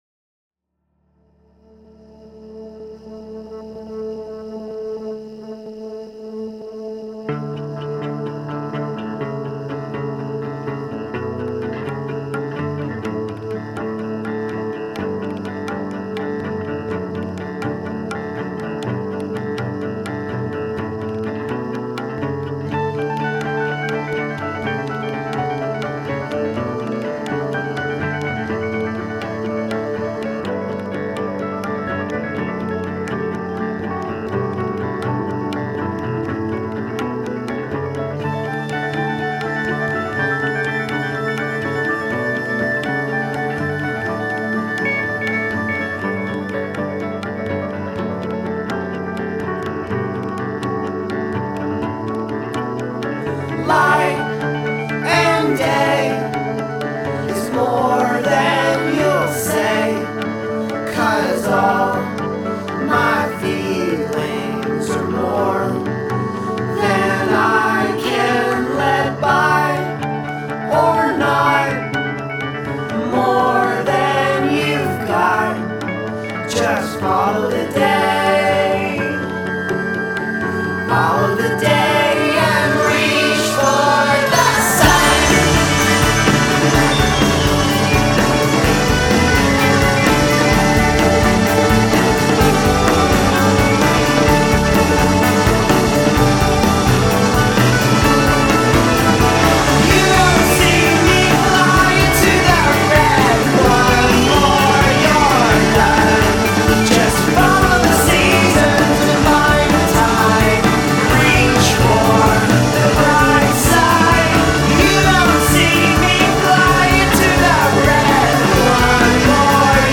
choral-symphonic-pop-rock